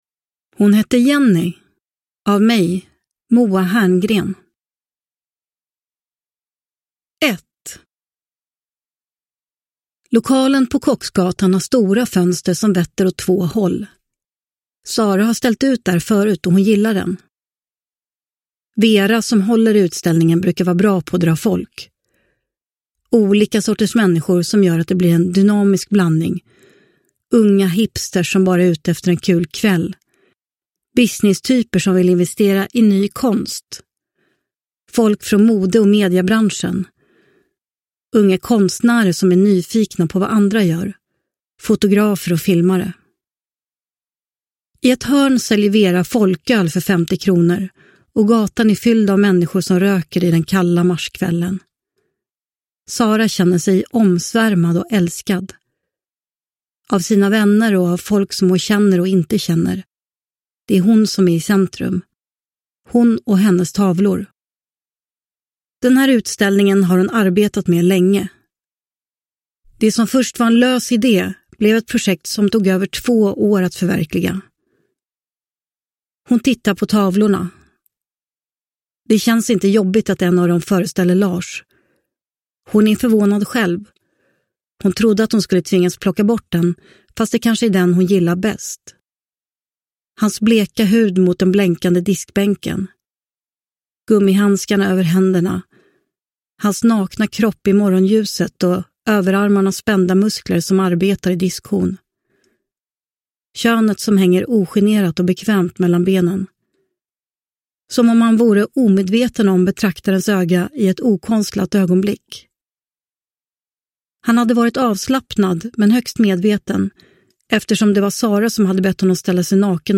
Hon hette Jennie – Ljudbok – Laddas ner
Uppläsare: Moa Herngren